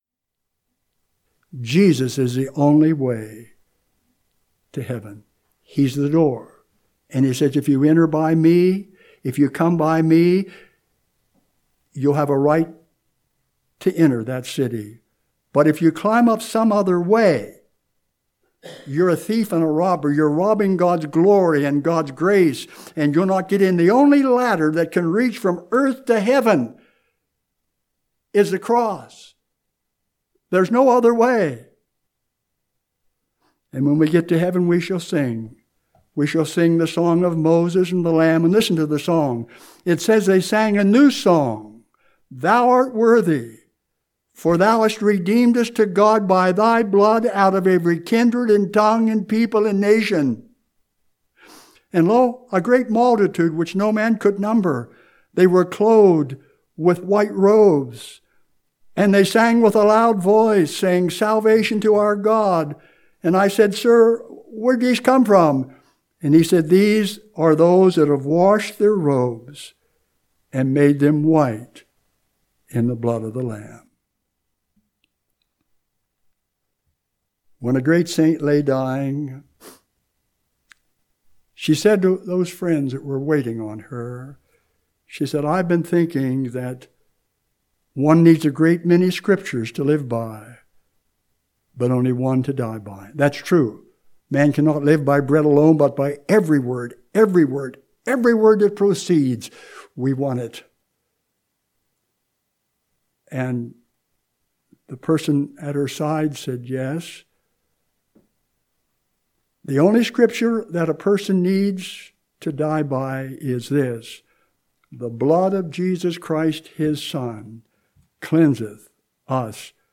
Note: Most of the audio for this sermon was lost due to an unexpected computer reboot while we were recording. What is posted is the conclusion and a brief story about the closing hymn.